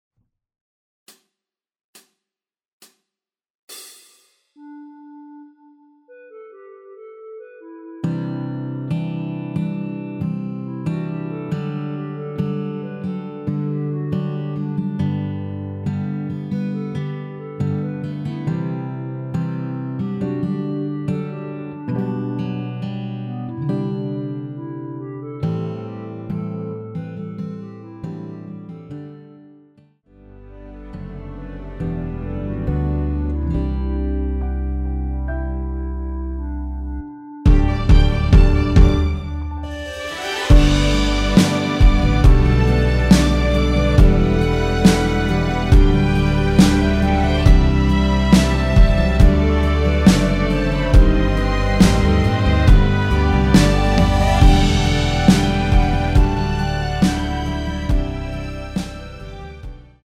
전주 없이 시작하는 곡이라서 시작 카운트 만들어놓았습니다.(미리듣기 확인)
원키에서(-1)내린 (1절앞+후렴)으로 진행되는 멜로디 포함된 MR입니다.
앞부분30초, 뒷부분30초씩 편집해서 올려 드리고 있습니다.
중간에 음이 끈어지고 다시 나오는 이유는